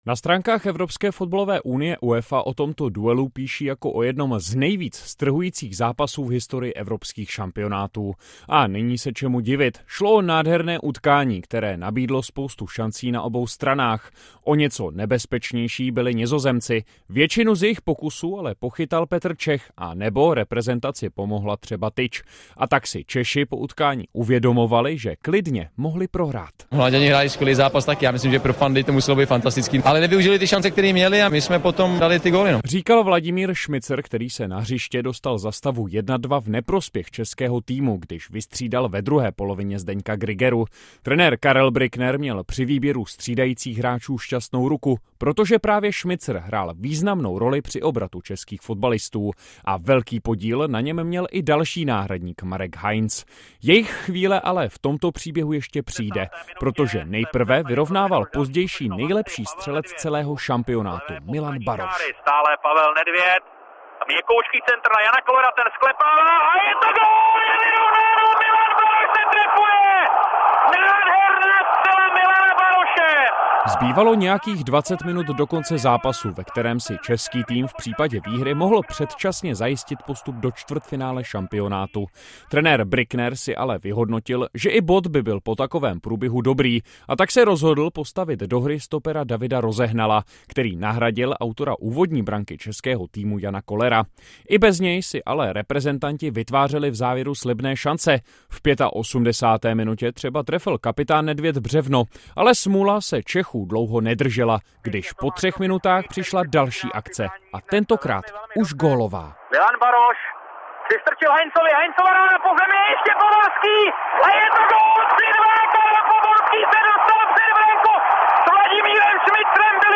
Na place: Hosty hlavně ze sportovního prostředí zvou do studia přední čeští herci známí např. z rolí v seriálu a filmu Okresní přebor a sportovní fanoušci - 23.10.2024